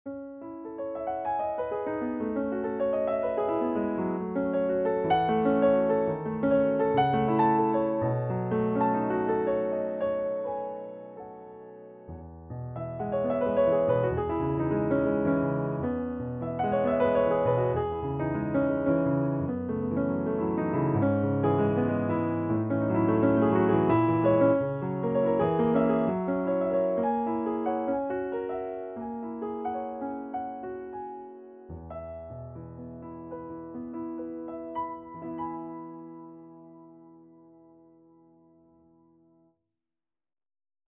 CN301-SK-EX-Mellow.mp3